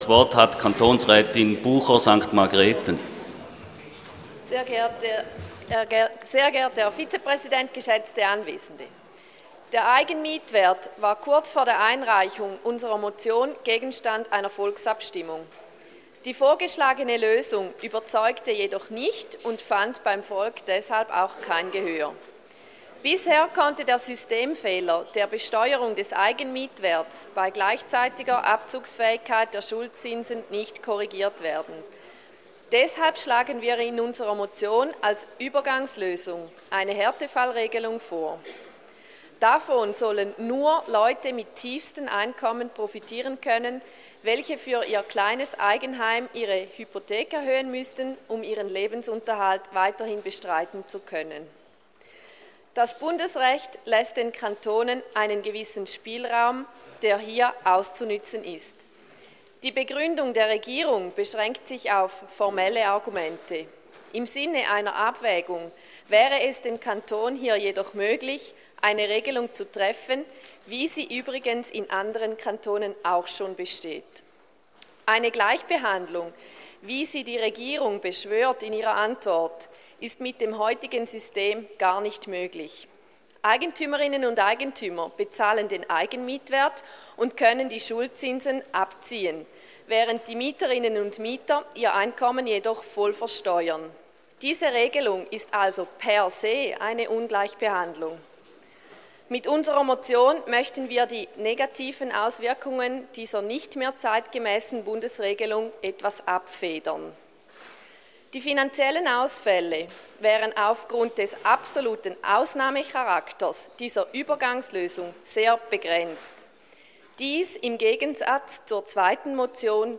Session des Kantonsrates vom 25. bis 27. Februar 2013